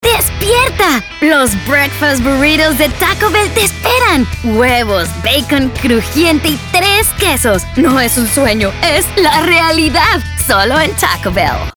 Commercial
Energetic - Playful